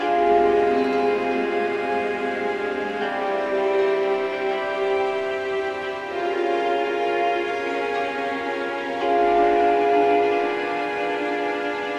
打击乐 53 80 Bpm
标签： 80 bpm Ethnic Loops Percussion Loops 2.02 MB wav Key : Unknown
声道立体声